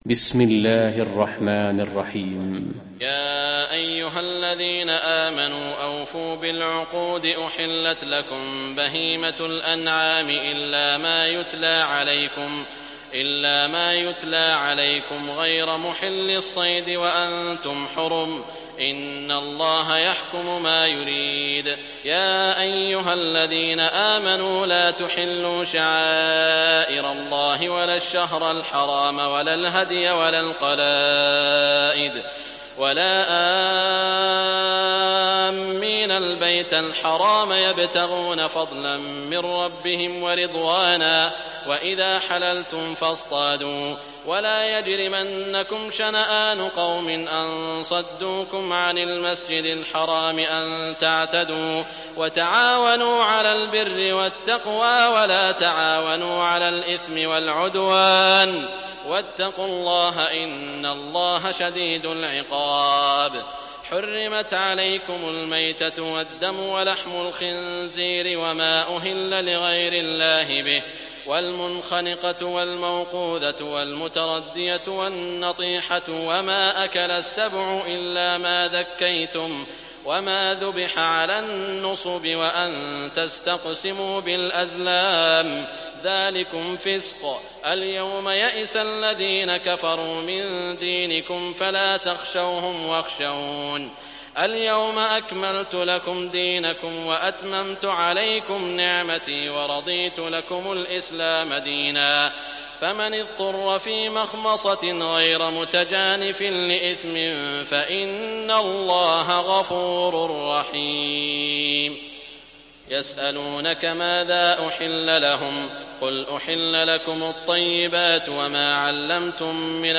دانلود سوره المائده mp3 سعود الشريم روایت حفص از عاصم, قرآن را دانلود کنید و گوش کن mp3 ، لینک مستقیم کامل